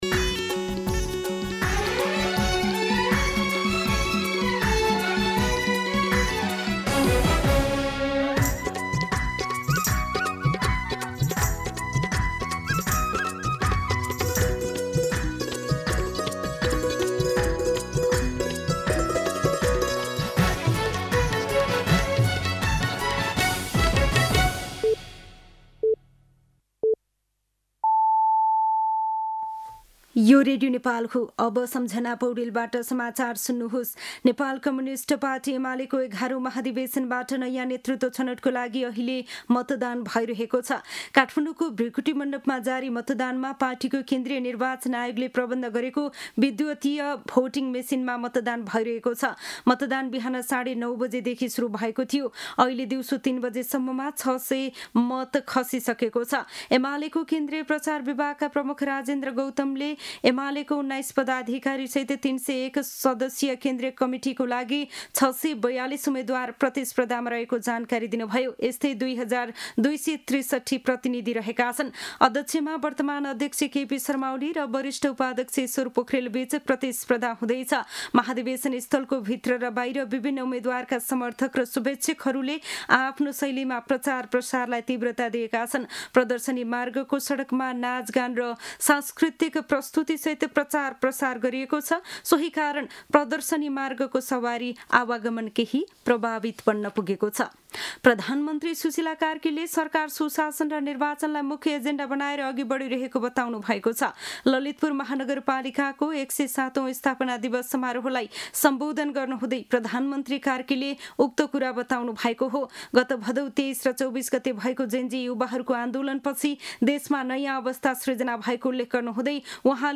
दिउँसो ४ बजेको नेपाली समाचार : २ पुष , २०८२